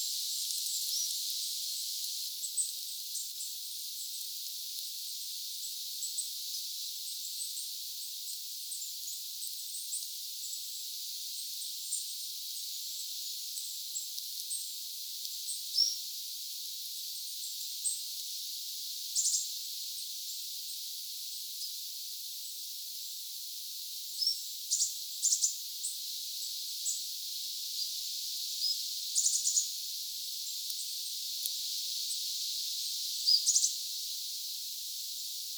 kuusitiaisen ääntelyä, sarjaääntelyjä
kuusitiaisen_aantelya_hippiaisia_sarjaaanet_ovat_kuusitiaisen_aantelyja.mp3